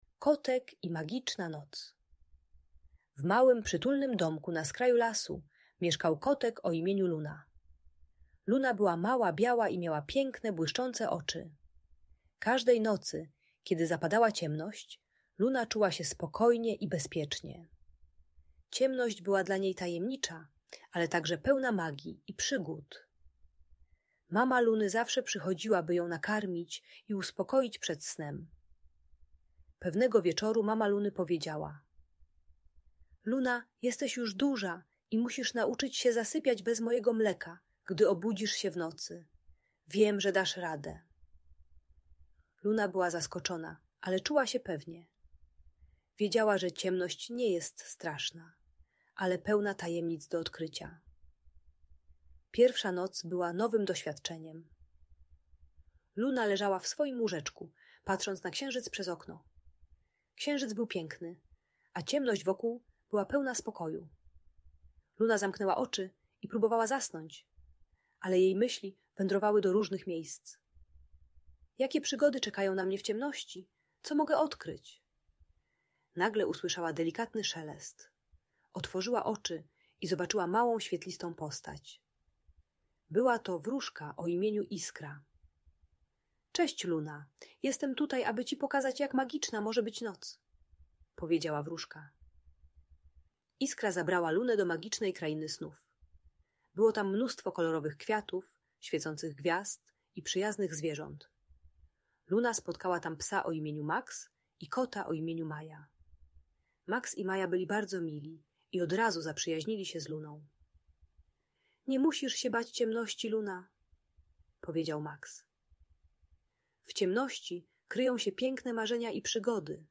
Kotek i Magiczna Noc - Usypianie | Audiobajka